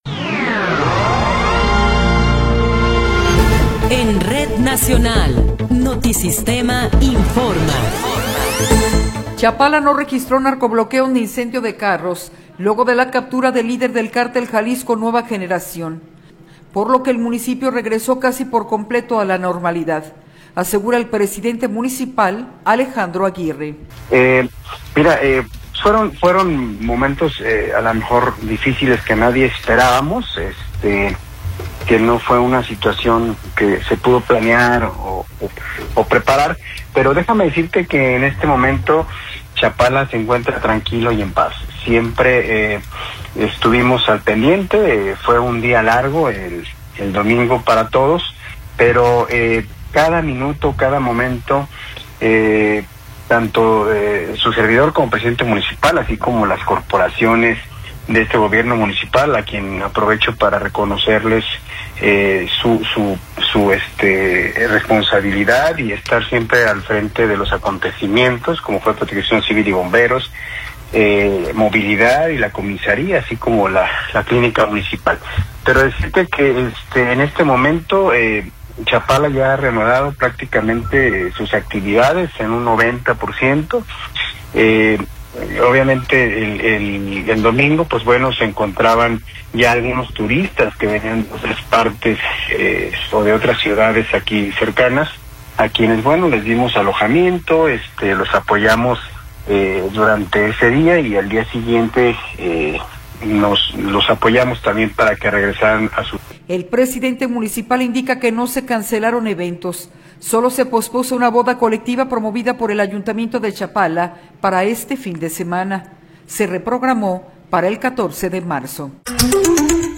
Resumen informativo Notisistema, la mejor y más completa información cada hora en la hora.